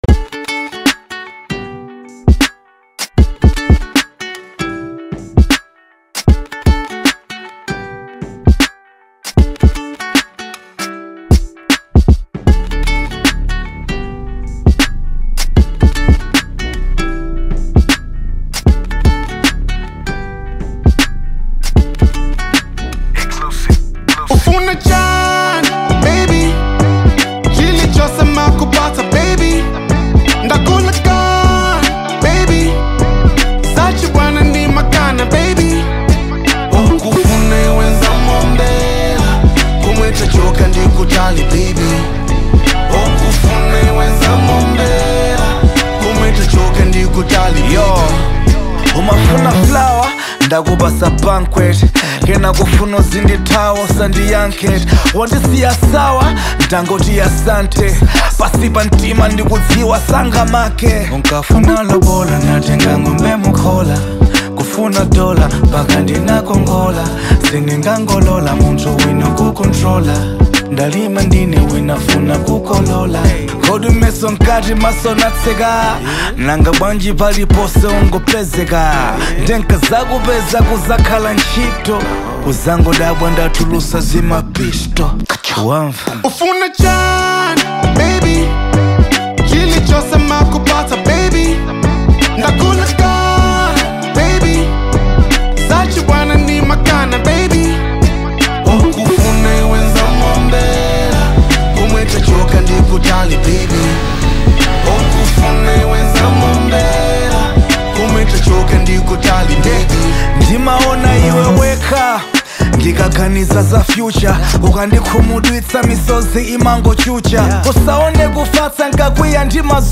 Genre : Afro Beat